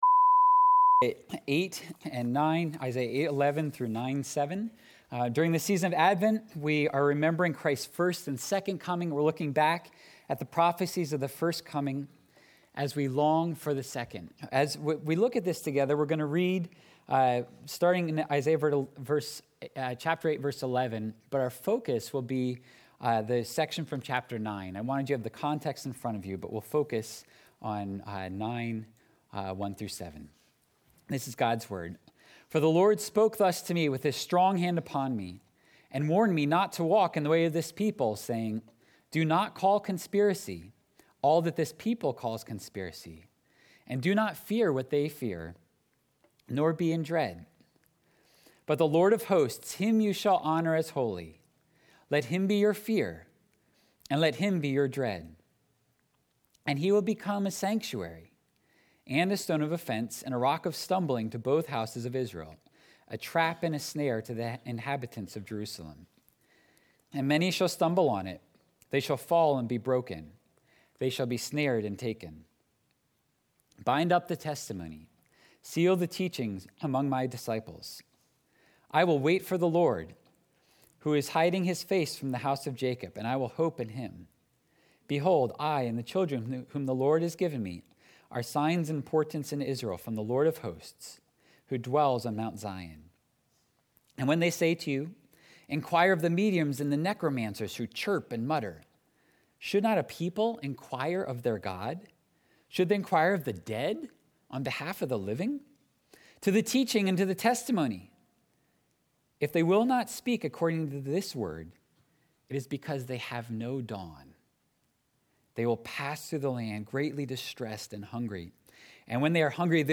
Sunday Worship – December 12, 2021 – Light in the Darkness